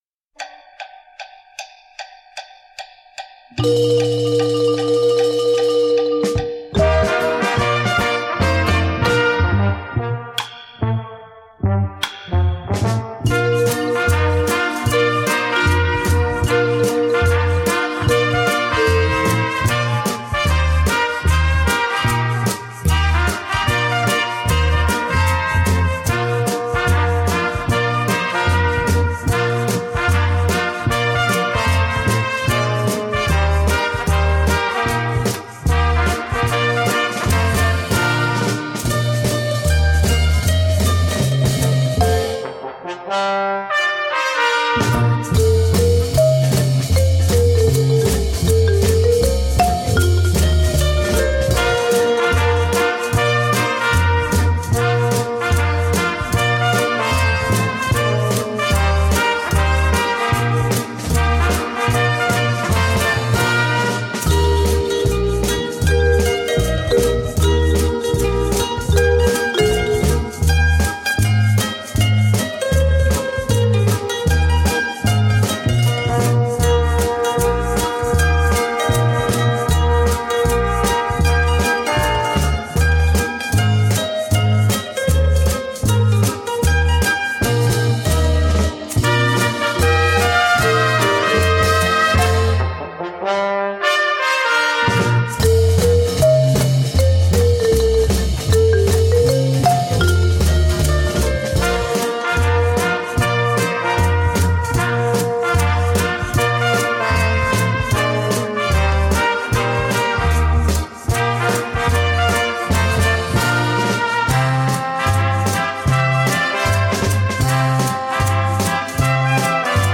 LP